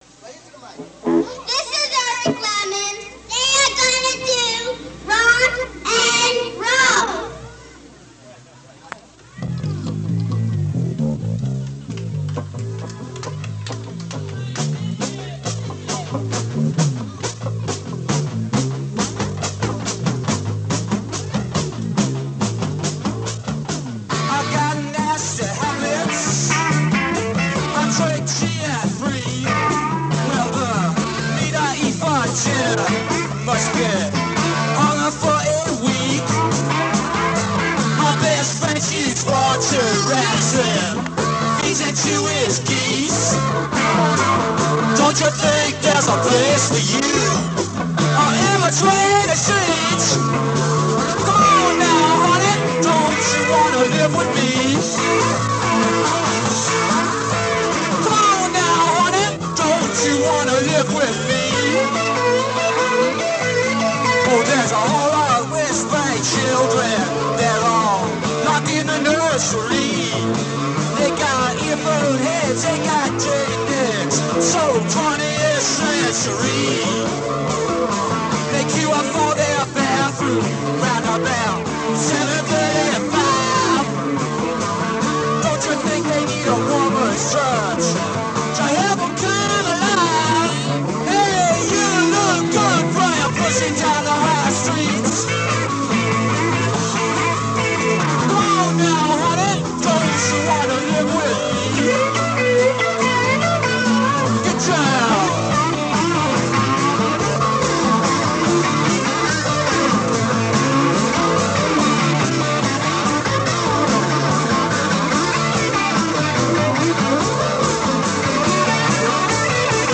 an outdoor concert at a bikers' picnic in August 1975